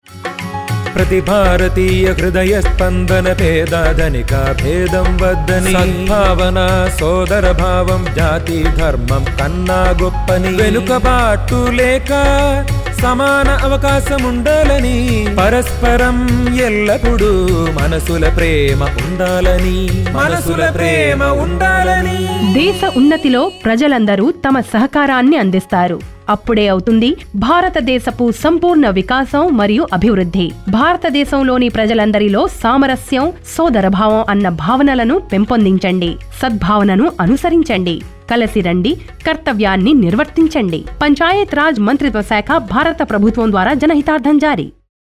227 Fundamental Duty 5th Fundamental Duty Sprit of common brotherhood Radio Jingle Telugu